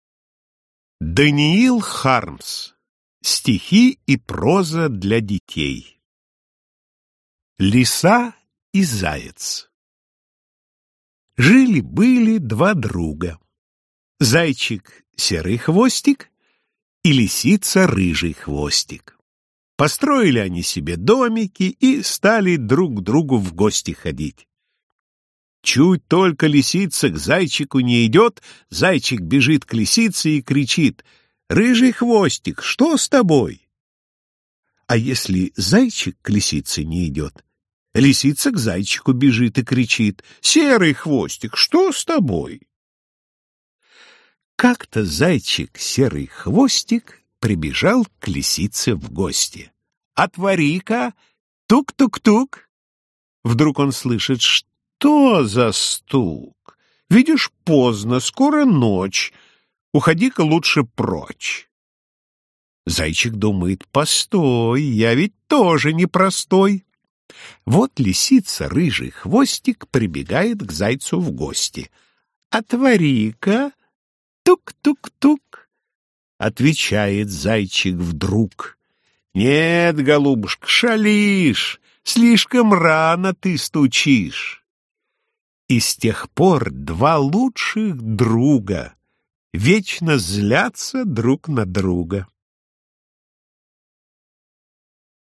Аудиокнига Иван Топорышкин. Стихи и проза Даниила Хармса | Библиотека аудиокниг